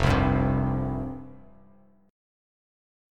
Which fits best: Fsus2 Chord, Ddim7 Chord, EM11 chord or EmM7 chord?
Fsus2 Chord